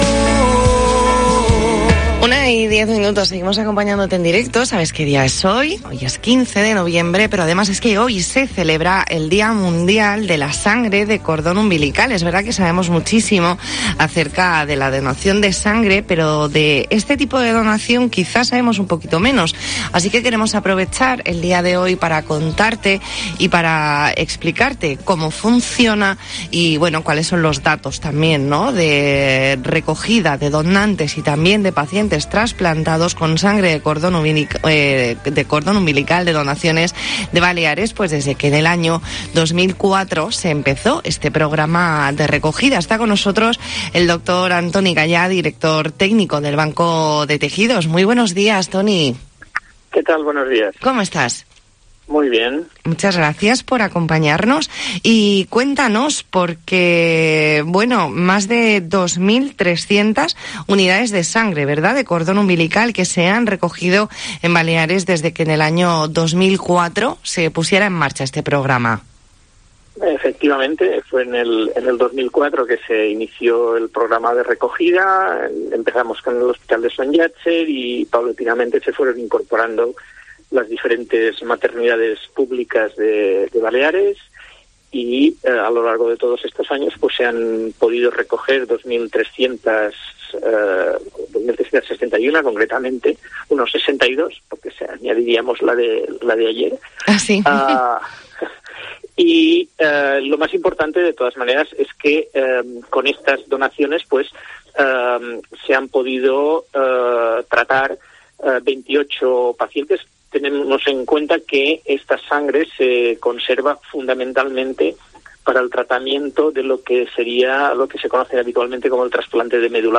Entrevista en La Mañana en COPE Más Mallorca, lunes 15 de noviembre de 2021.